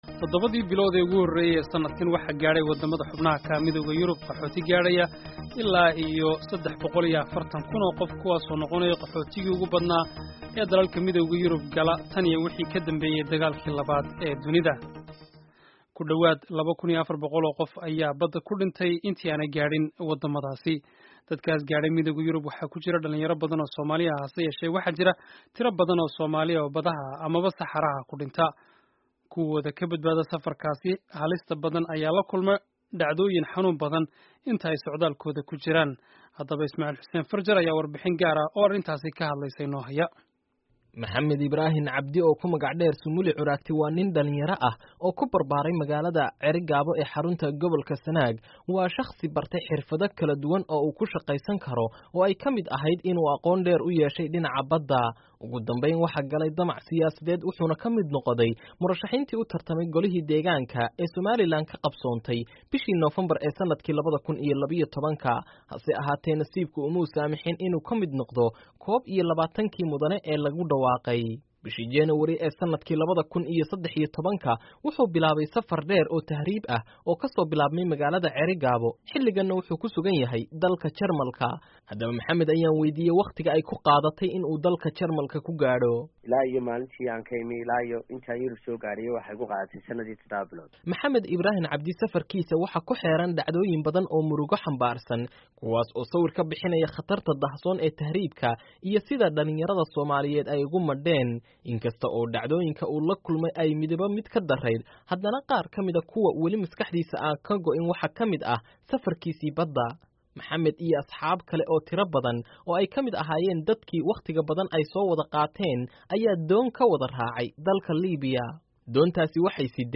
Warbixinta Tahriibka